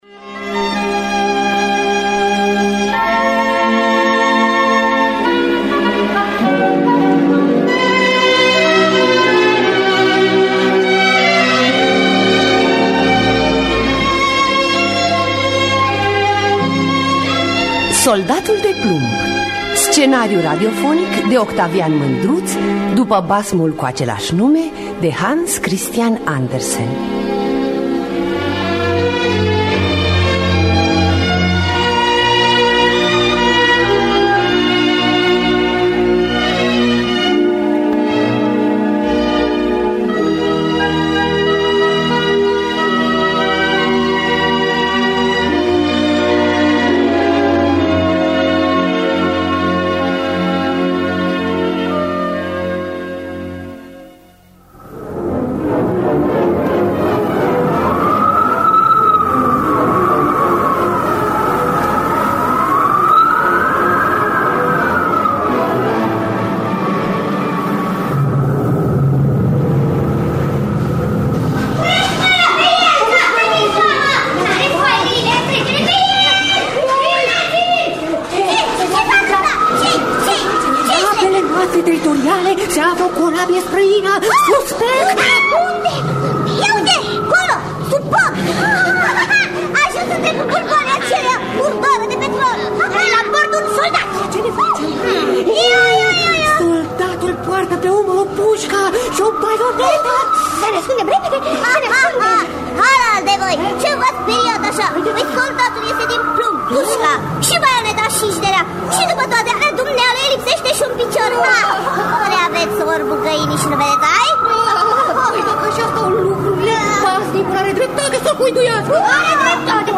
Scenariu radiofonic de Octavian Mândruț.